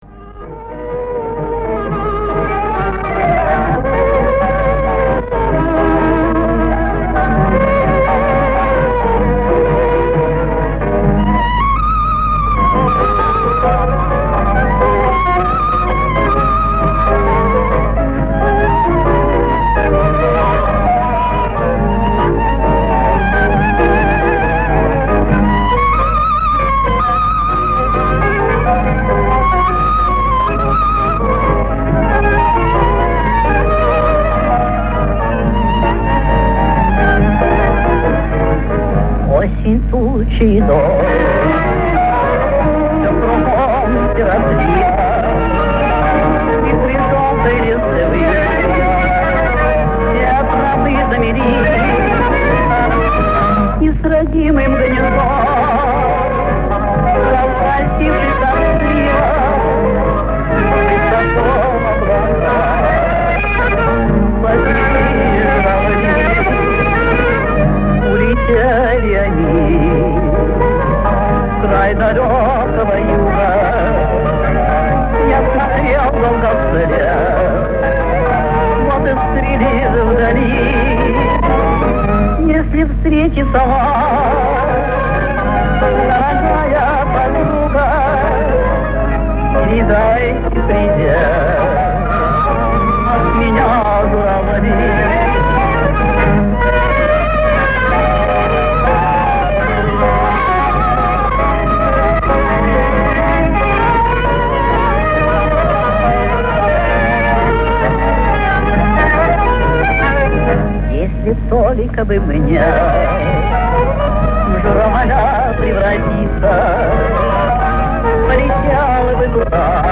Мелодия и текст воспроизведены с записи на грампластинке